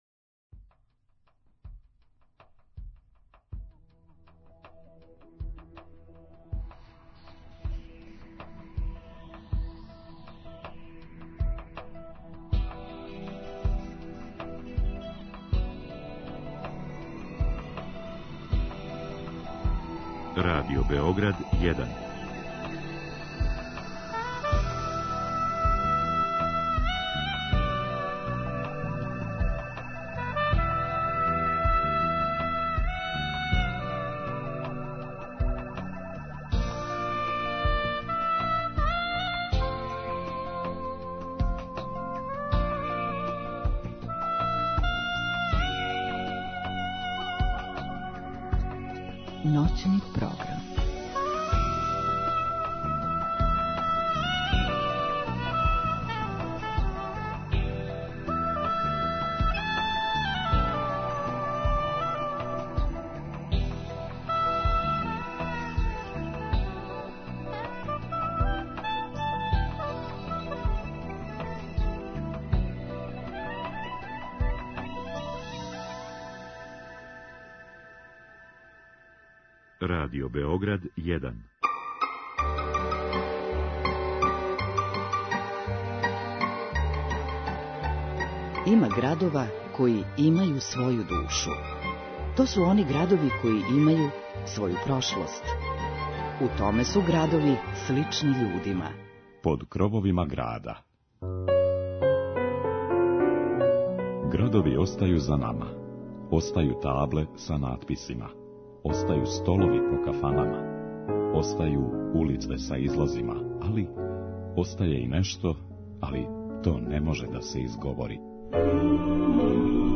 музику западне Србије